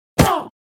Dźwięk obrażeń wiedźmy nr.1
Witchhurt1.wav